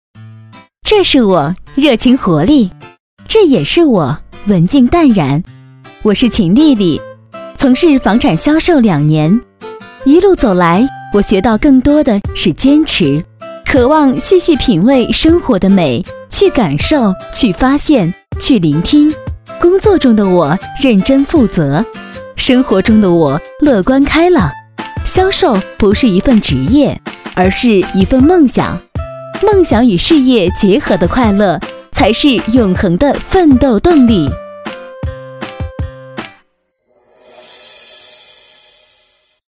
C女106号 | 声腾文化传媒
【专题】个人介绍.mp3